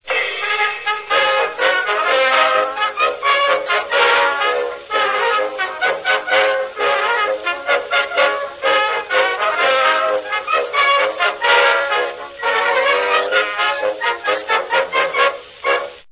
Wax cylinder excerpts . . .
•  New York Military Band, 1909